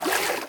swim4.ogg